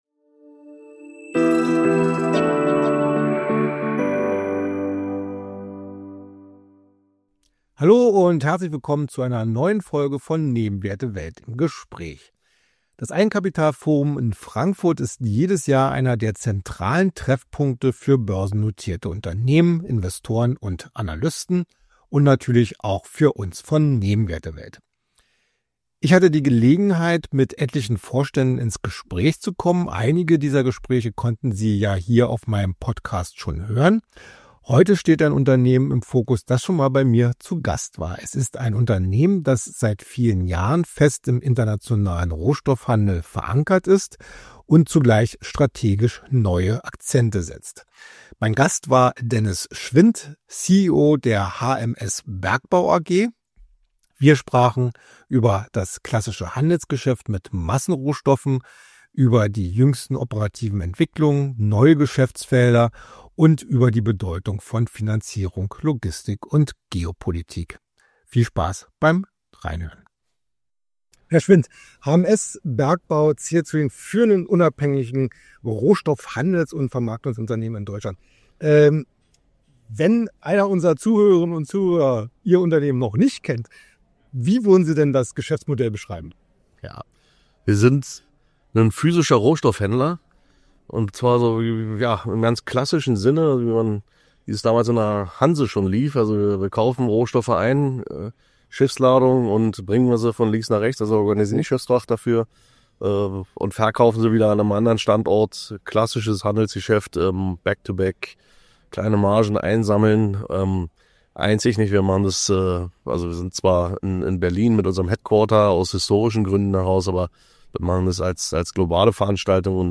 Ein Gespräch über Rohstoffhandel, strategisches Wachstum und die Balance zwischen Stabilität und Expansion.